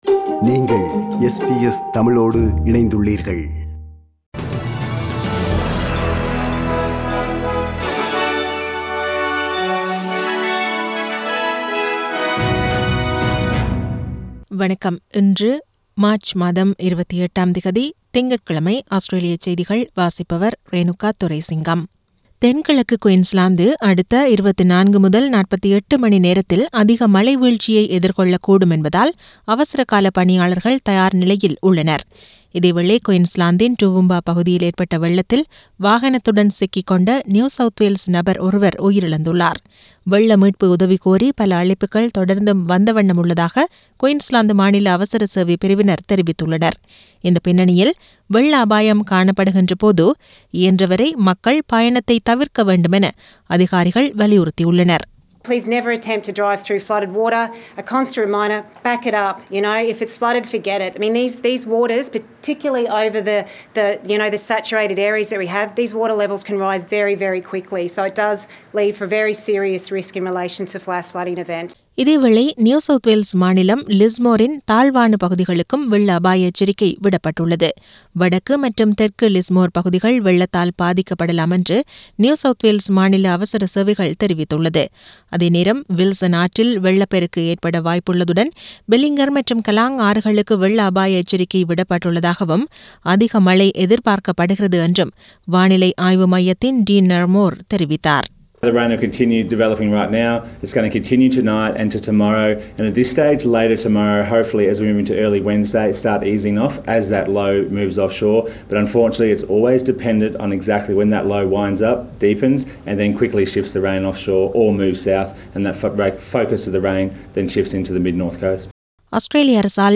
Australian news bulletin for Monday 28 Mar 2022.